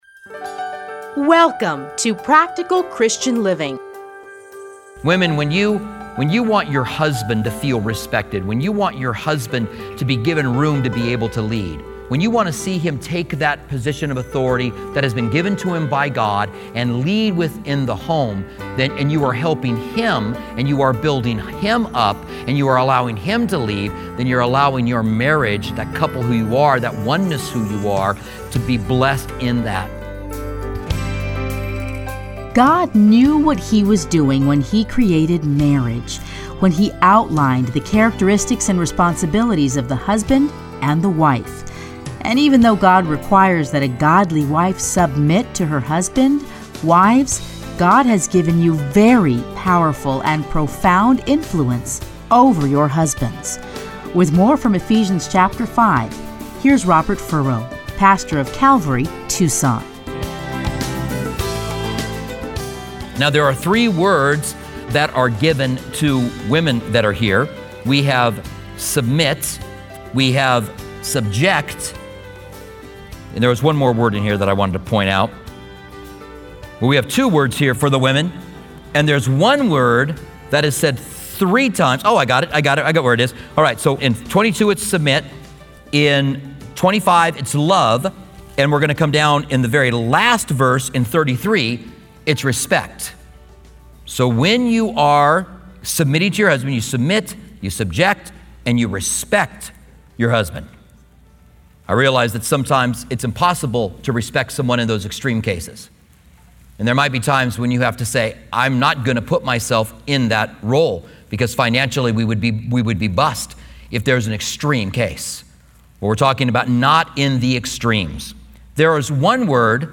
Playlists Commentary on Ephesians Download Audio